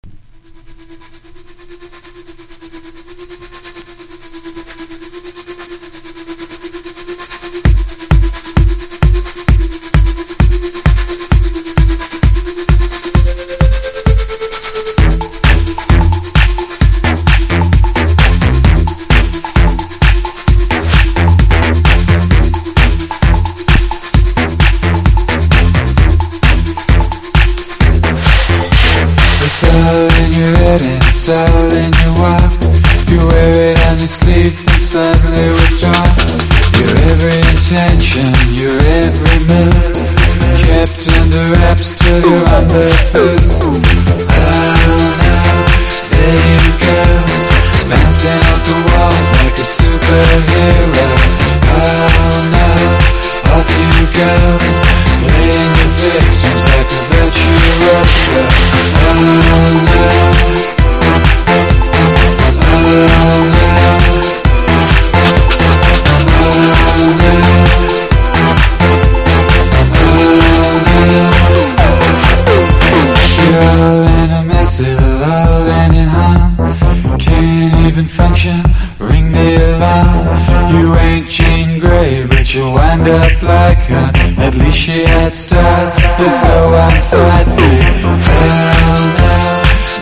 NU-DISCO / RE-EDIT# BREAK BEATS / BIG BEAT
ブルックリンのインディ・ディスコ・バンド
レイヴィーなブレイクビーツ・テクノのサウンドに、気だるく甘いヴォーカルを見事に溶け込ませた激キラー・チューン！！